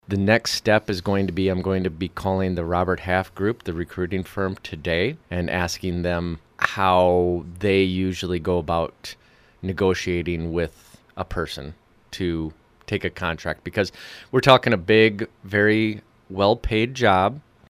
Mayor Ried Holien.